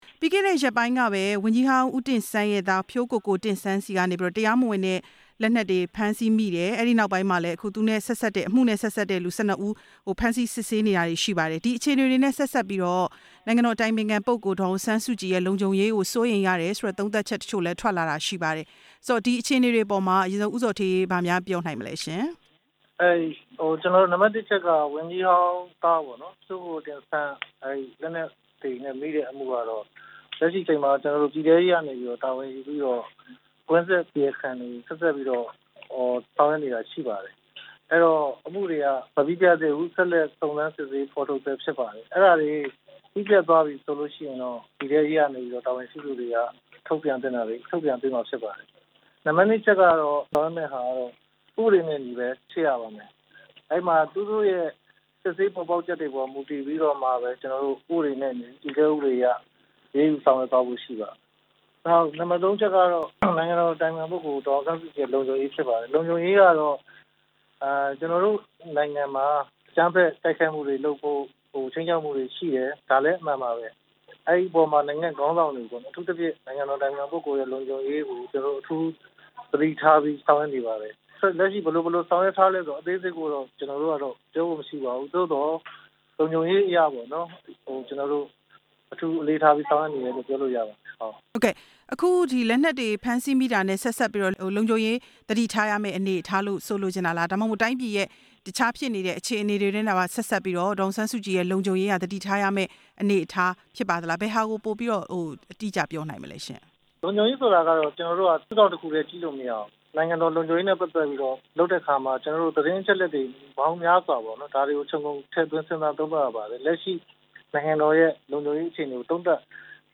နိုင်ငံတော်အတိုင်ပင်ခံ ပုဂ္ဂိုလ်ဒေါ်အောင်ဆန်းစုကြည်ရဲ့ လုံခြုံရေးကို ပိုပြီး အလေးထားဆောင်ရွက်နေတယ်လို့ နိုင်ငံတော်အတိုင်ပင်ခံရုံး ဝန်ကြီးဌာန ညွှန်ကြားရေးမှူးချုပ် ဦးဇော်ဌေးက ပြောပါတယ်။
ဆက်သွယ်မေးမြန်းထားပါတယ်။